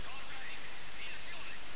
Download - Sounds Effects (SFX) - 064.wav
Type: Sound Effect